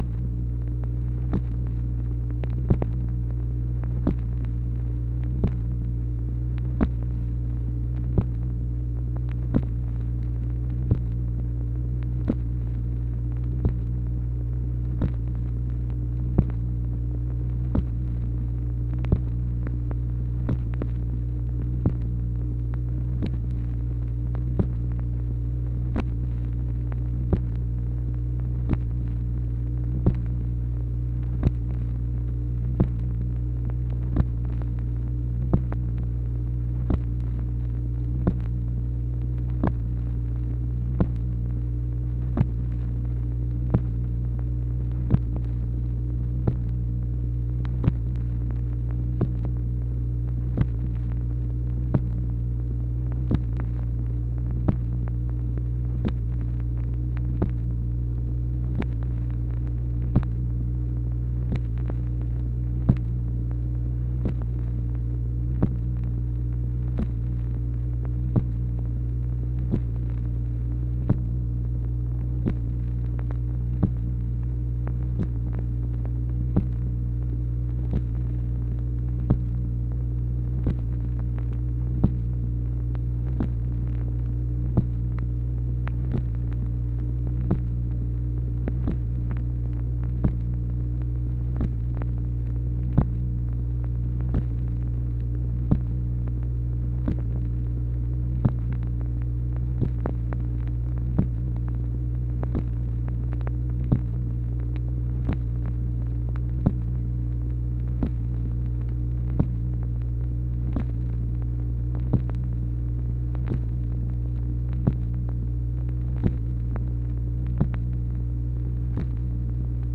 MACHINE NOISE, January 5, 1964
Secret White House Tapes | Lyndon B. Johnson Presidency